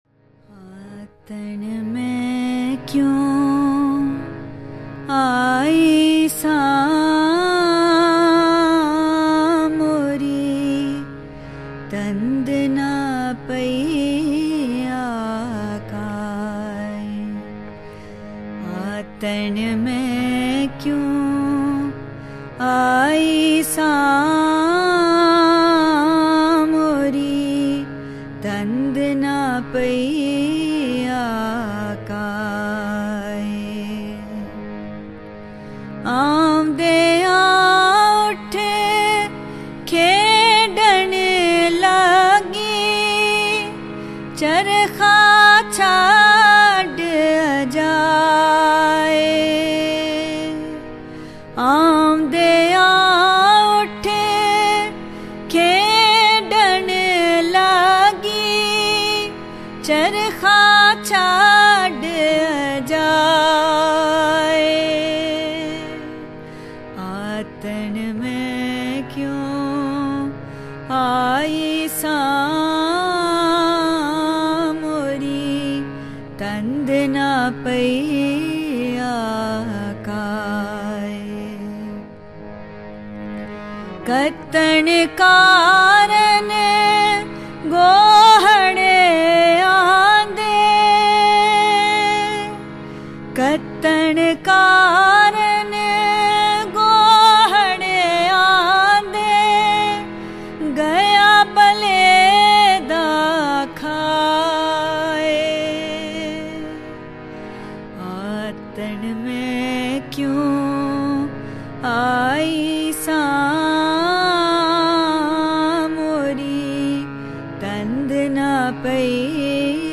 Punjabi Sufiana Kalam